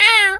bird_vulture_hurt_06.wav